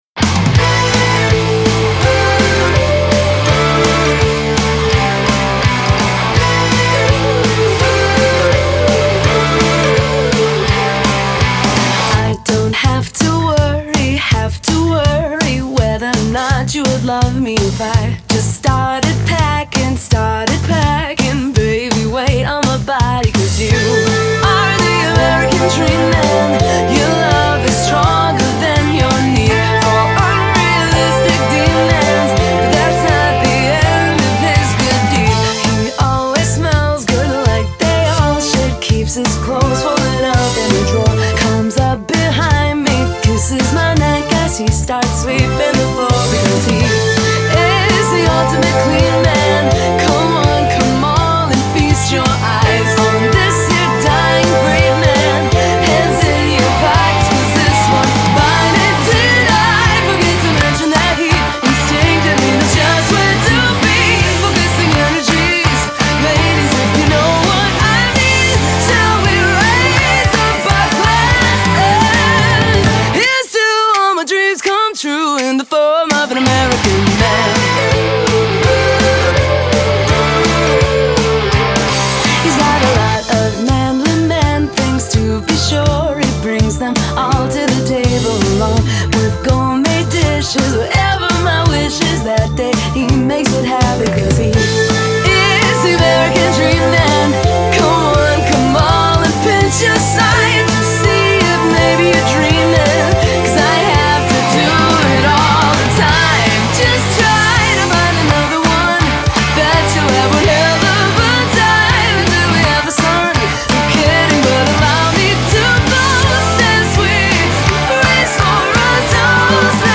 • Genre: Poprock